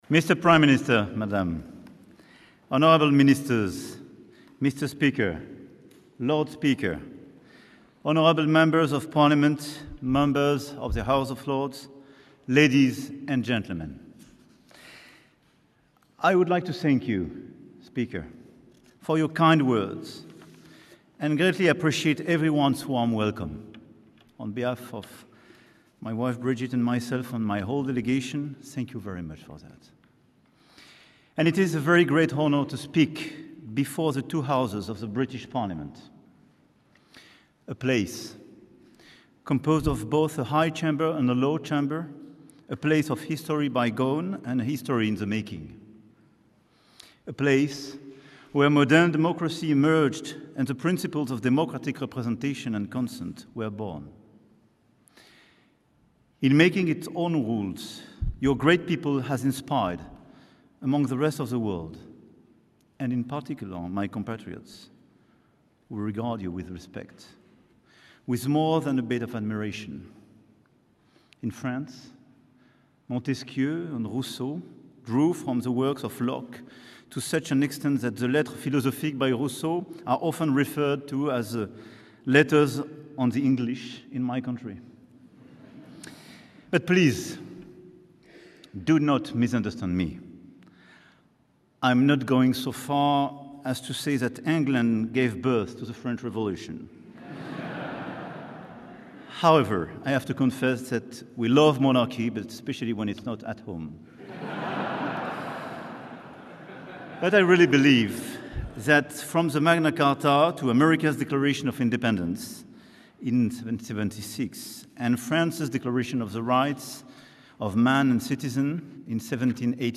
Emmanuel Macron - Address to Both Houses of the UK Parliament (transcript-audio-video)